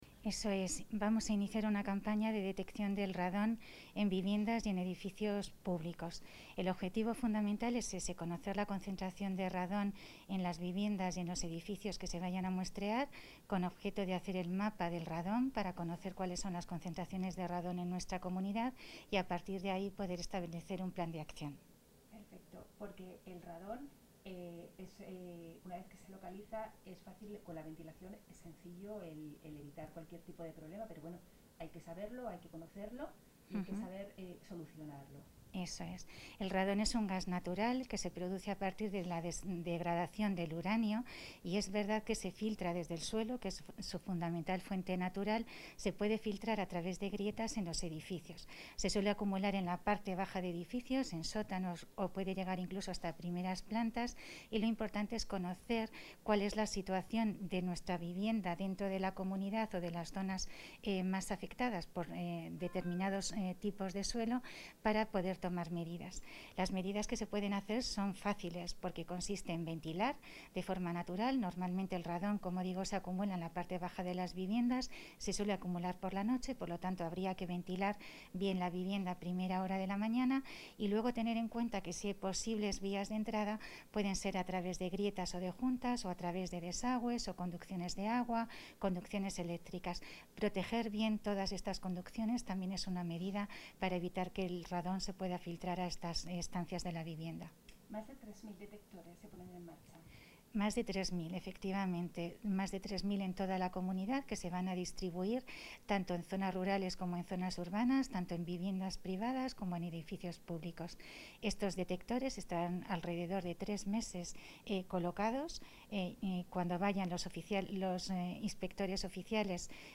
Declaraciones de la directora general de Salud Pública.